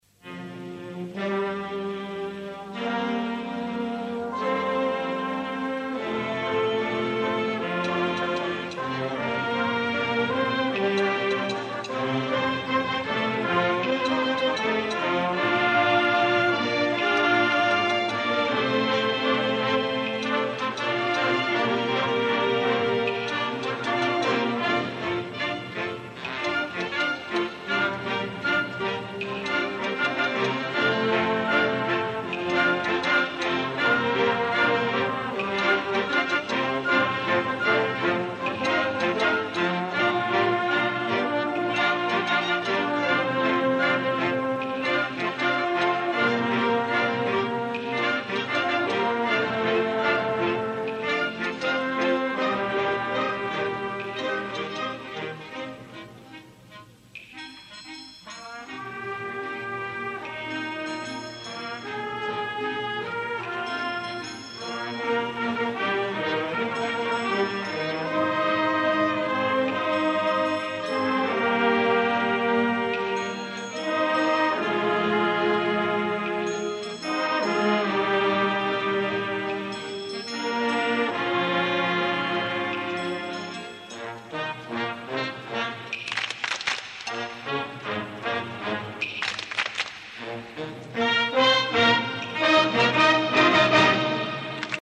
Concert extraordinari 1988. Esglesia parroquial de Porreres Nostra Senyora de la Consolació.